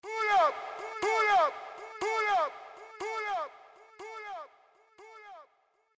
PULL UP Sound Effect Jingle Sampler Effect
HD Pull Up Sound Effect Jingle Sampler Effect.